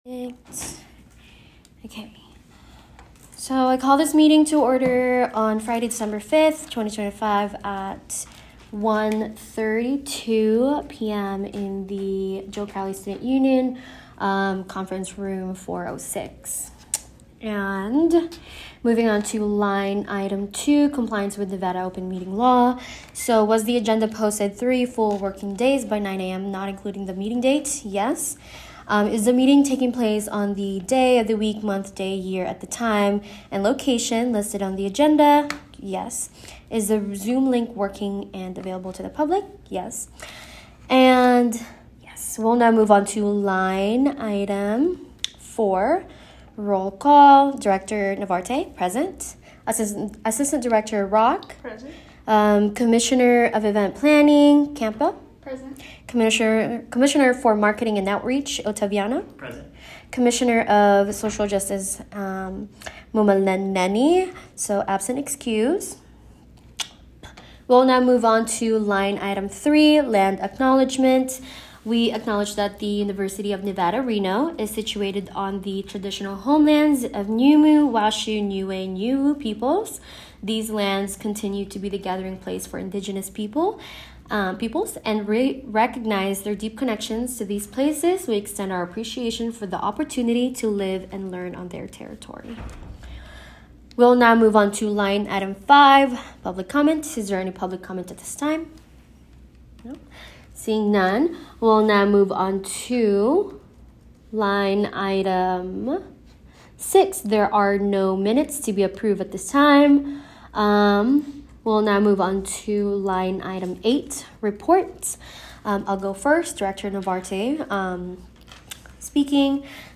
Meeting Type : Diversity and Inclusion
Location : Joe Crowley Student Union 406 Conference Room